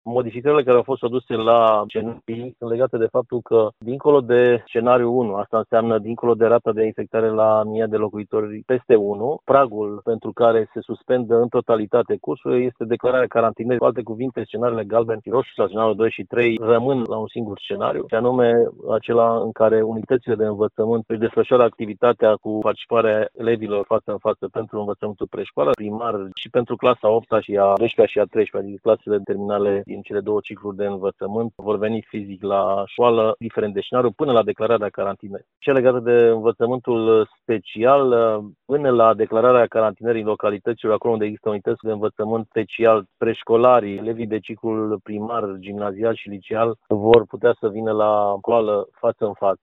De asemenea, ordinul pemite participarea la cursuri și a elevilor din învățământul special, indiferent de clasă, explică șeful Inspectoratului Școlar Timiș, Marin Popescu.